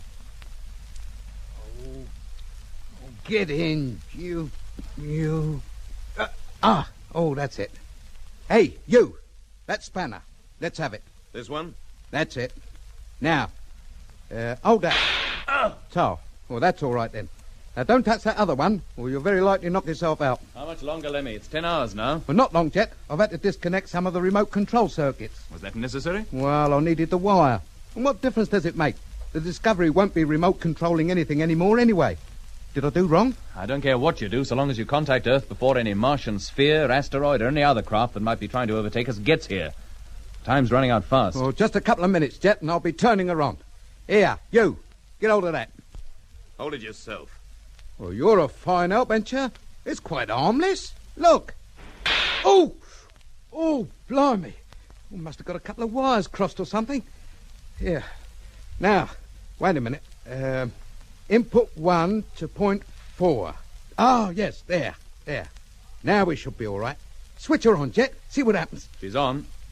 Nu schijnen Britten over het algemeen erg beleefd te zijn, maar voor mij wederom een duidelijk verschil in acteren (of was het ook de regie?). Zelfs het sadistische lachje van Evans ontbreekt in de Britse versie.